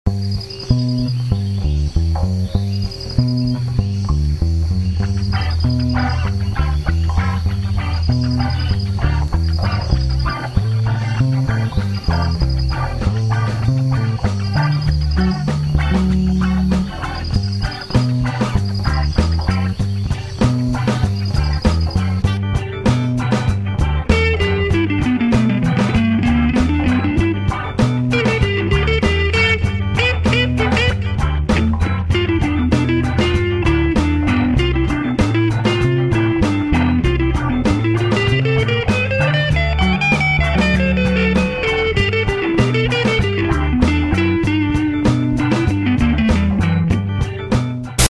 (0:47) Some shrimp tan along the river, listening ska